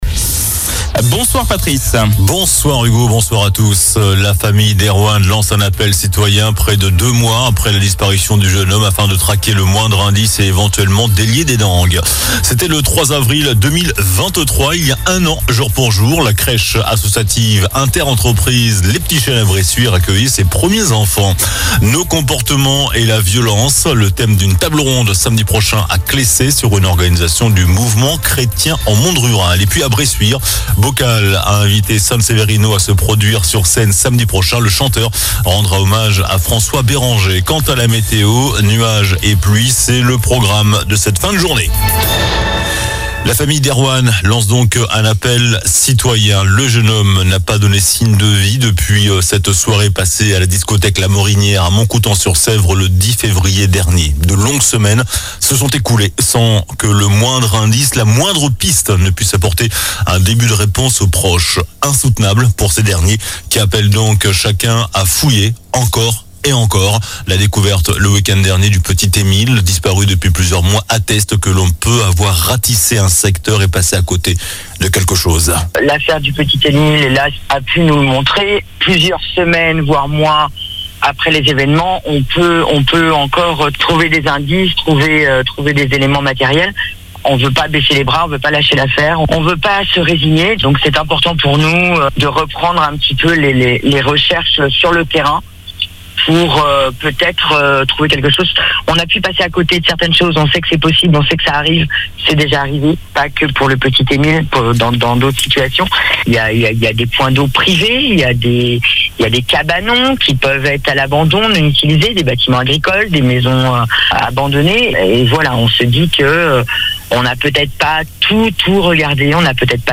JOURNAL DU 03 AVRIL ( SOIR )